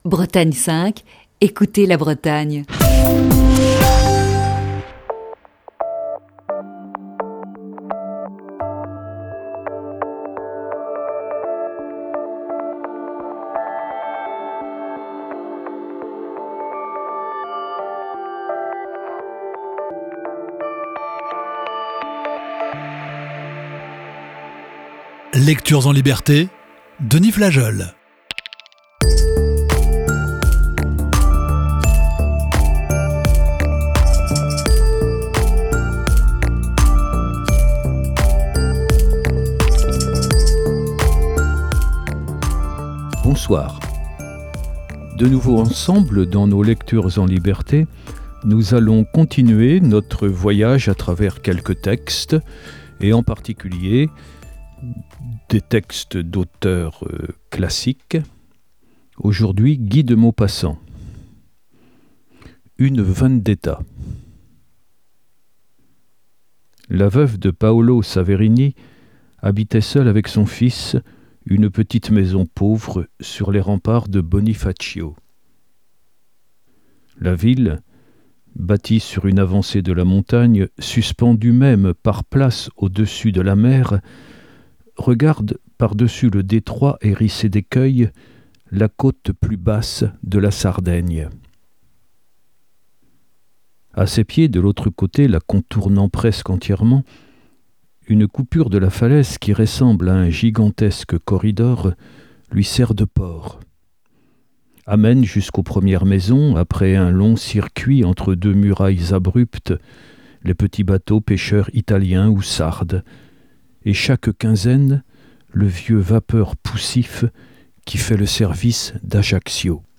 lit deux textes de Guy de Maupassant, "Une vendetta" et "Le petit fût".